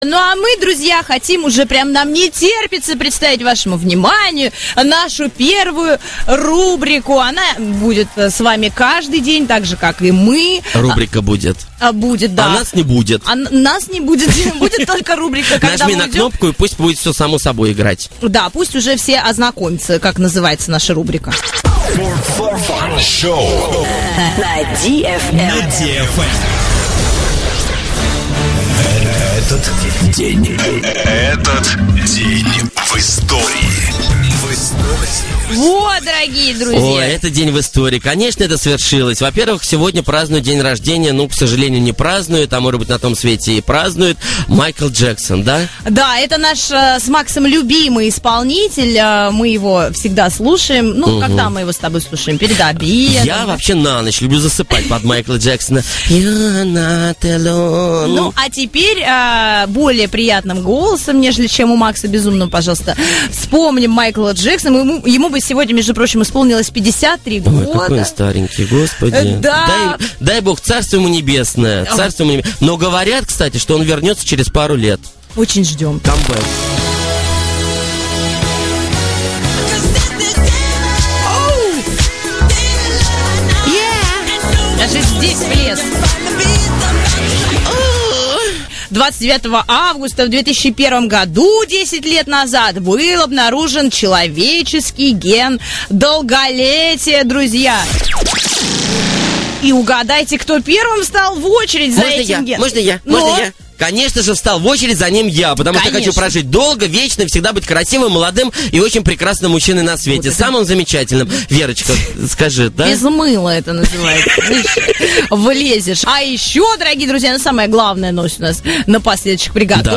4funshow на DFM. Запись эфира.
Эфир от 29 августа 2011 года, первый эфир шоу.